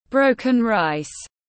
Cơm tấm tiếng anh gọi là broken rice, phiên âm tiếng anh đọc là /ˈbrəʊkən raɪs/
Broken rice /ˈbrəʊkən raɪs/